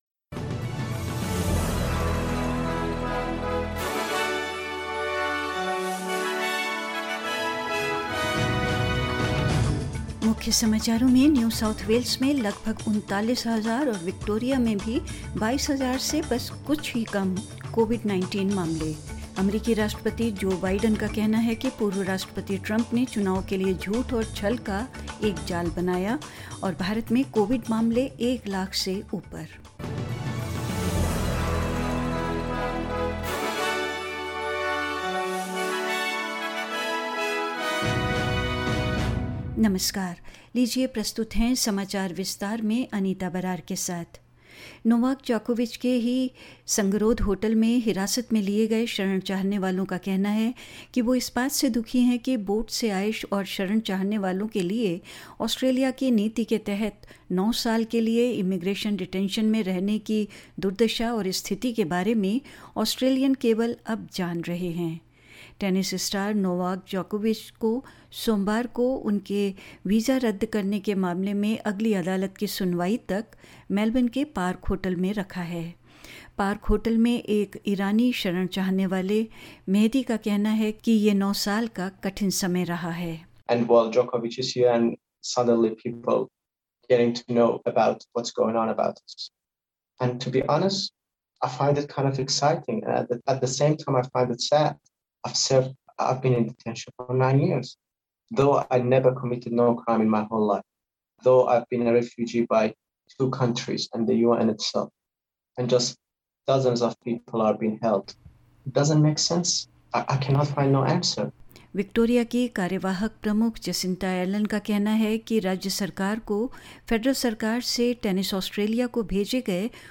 In this SBS Hindi news bulletin: New South Wales records nearly 39,000 new COVID-19 cases and Victoria just under 22,000; US President Joe Biden says former President Trump created a web of lies and deceit leading up to the elections; In India COVID-19 cases surpass one hundred thousand and more news